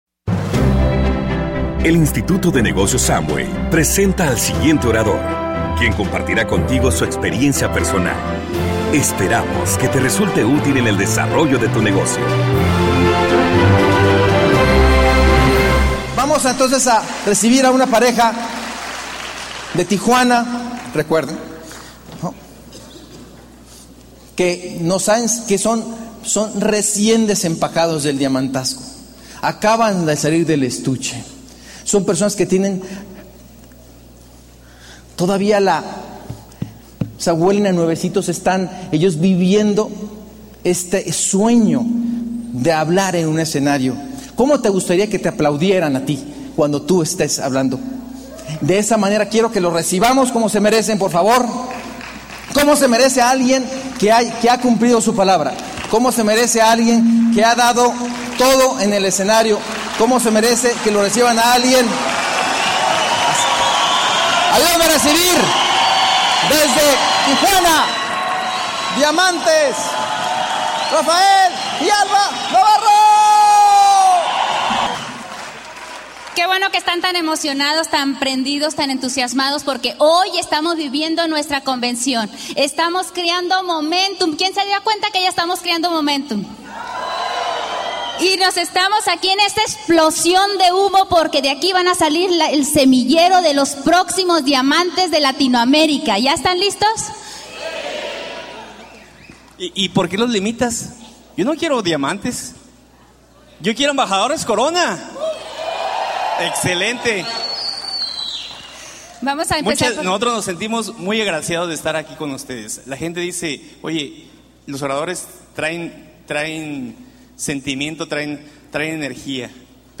En esta charla los oradores comparten como lograr un negocio balanceado, y redituable desde el principio y también invitan a que los latinos marquen una diferencia, y transformen sus pensamientos para construirse y ayudar a construir a alguien mas.